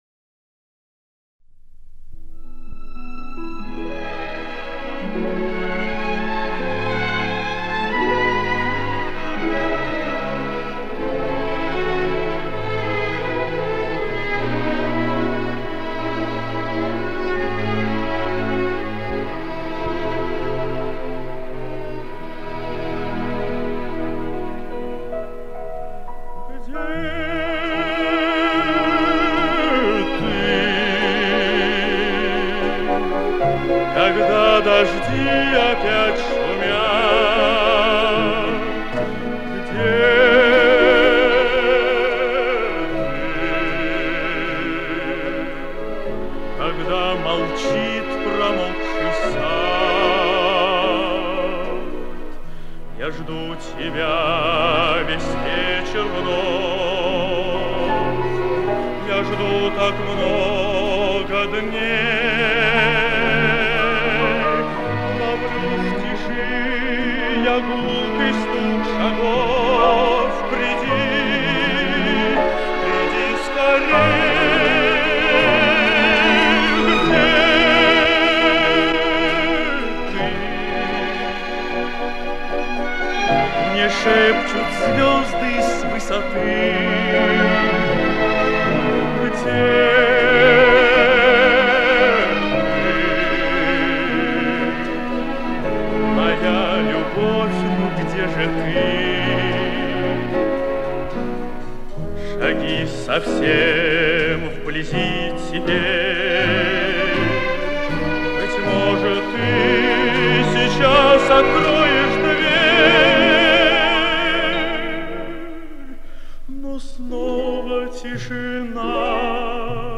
Это же исполнение по звучанию современно.